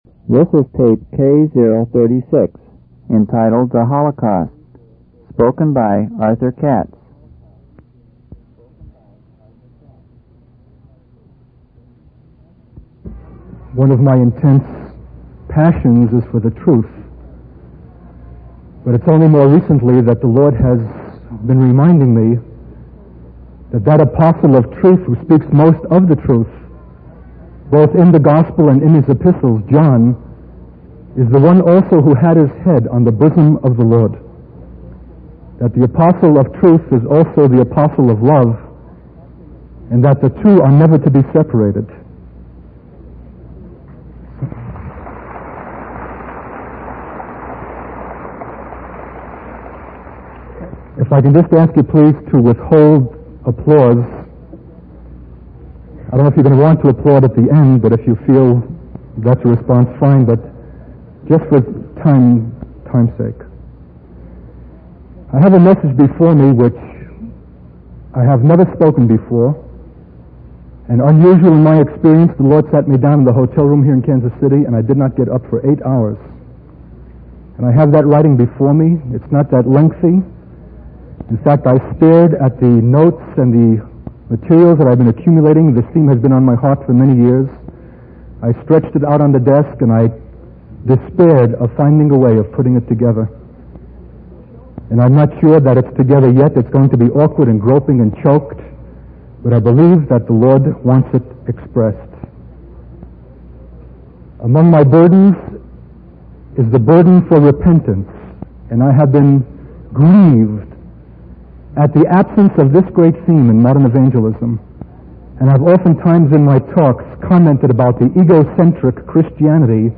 In this sermon, the speaker shares his burden for repentance and the absence of this message in modern evangelism. He emphasizes the need for Christians to understand their responsibility and guilt before God for the death of Jesus.